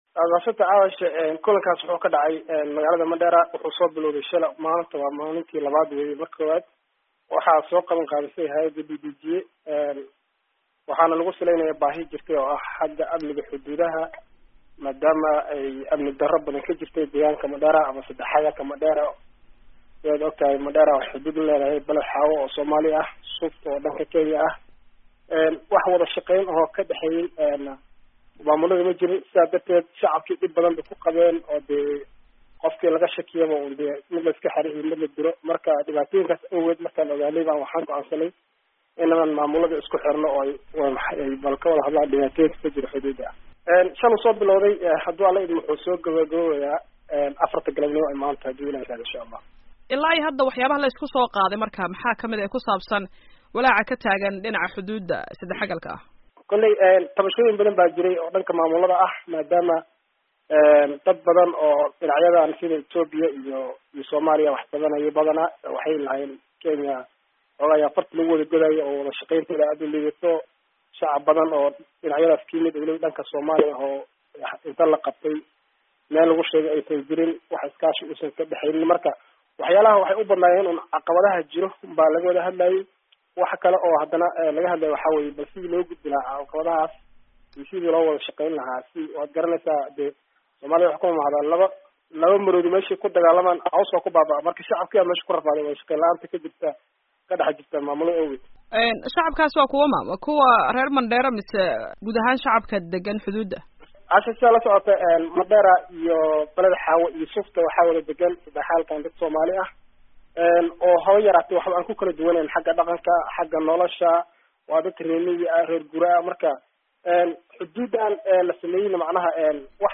khadka taleefanka ee Mandera kula xiriirtay